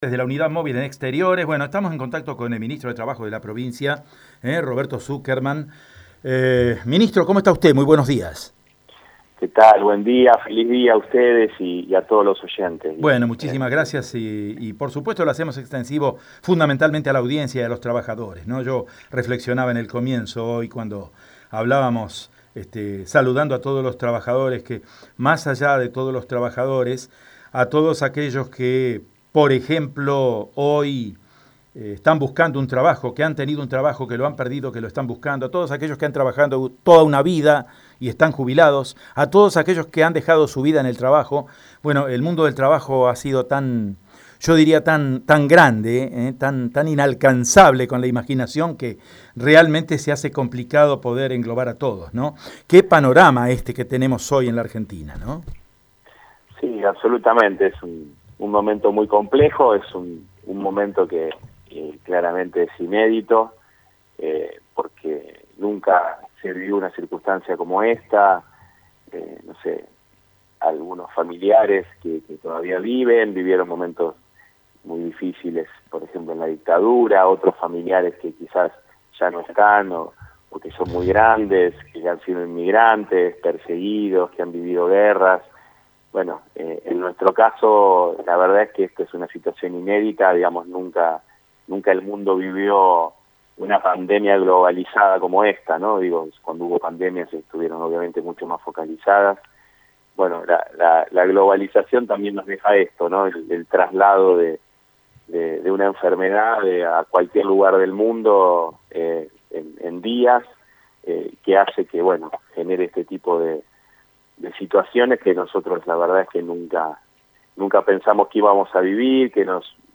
El ministro de Trabajo de la provincia de Santa Fe, Roberto Sukerman, brindó declaraciones exclusivas en Radio EME.